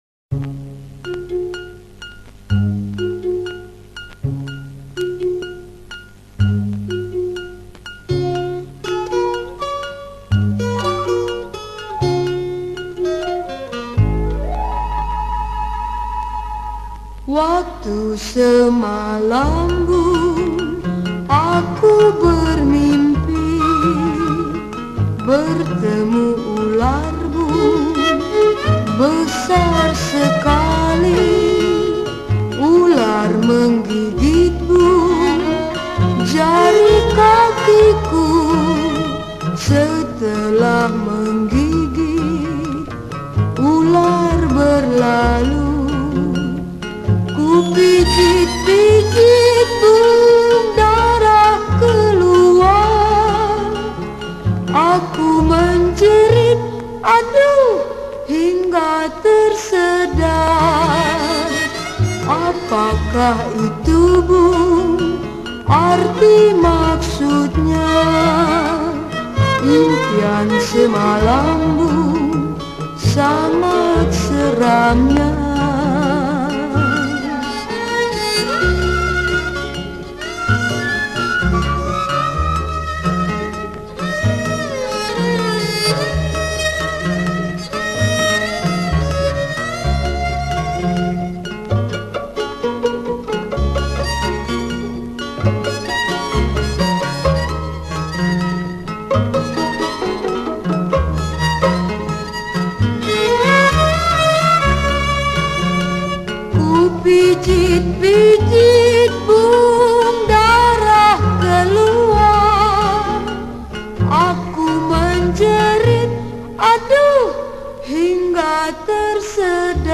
Rearranged in 2 parts harmony By